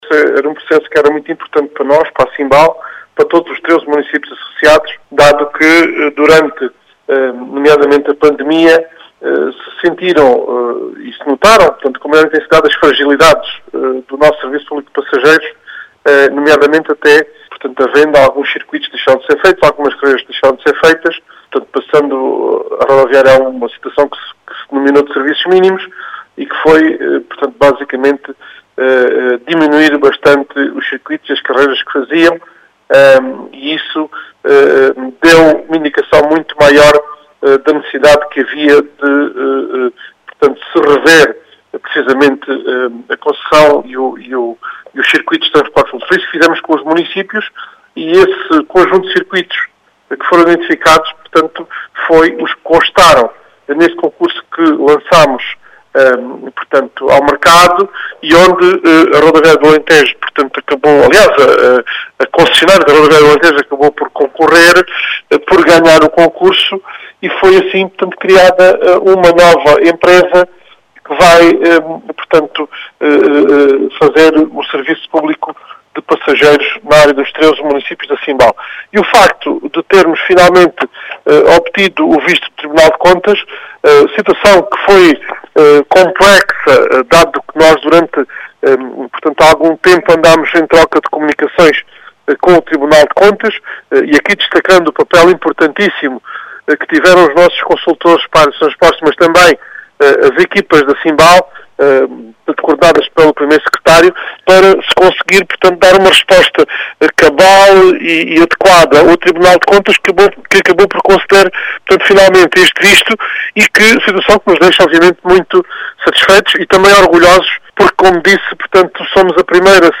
As explicações são de Jorge Rosa, presidente da Comunidade Intermunicipal do Baixo Alentejo que afirma que este era um “processo importante” para os municípios associados da CIMBAL, que segundo este estão “satisfeitos e orgulhosos” por poderem avançar com este processo.